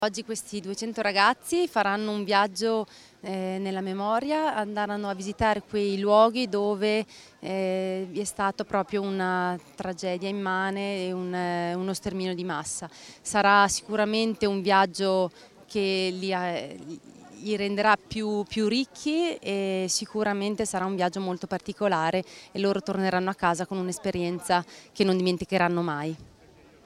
Sono partiti oggi da piazza Dante, presente l'assessore Stefania Segnana